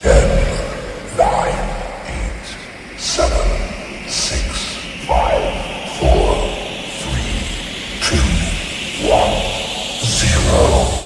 timer.ogg